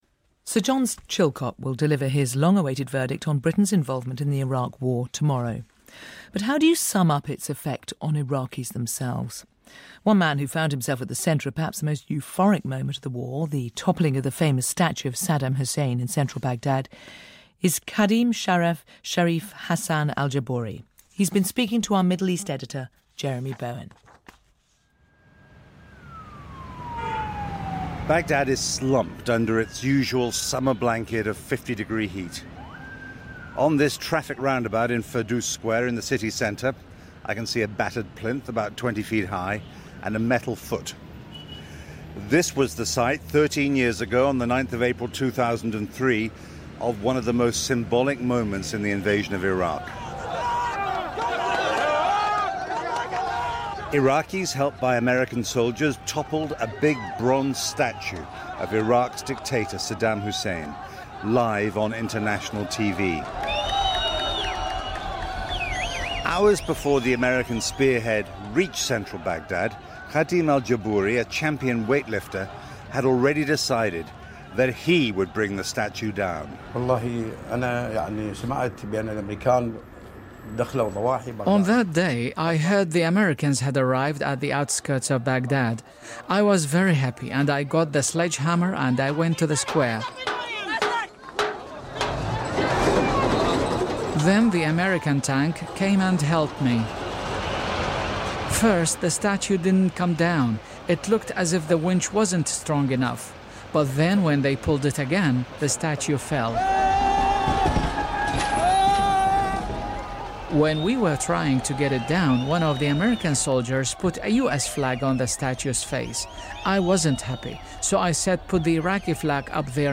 Jeremy Bowen on the consequences of the 2003 invasion in Iraq. One man who helped take down Saddam Hussein's statue explains why now he wishes to rebuild it. Our report on BBC Radio 4 Today Programme.